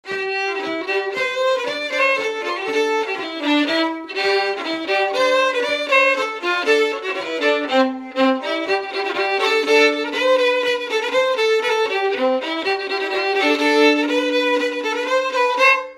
La vie que tu mènes Germaine Laforte : non-référencée - * Coirault : non-référencée - 141** Thème : 1074 - Chants brefs - A danser Résumé : La vie que tu mènes Germaine ne peut pas toujours durer, ce que tu gagnes dans une semaine, tu le dépenses dans une journée..
Pièce musicale inédite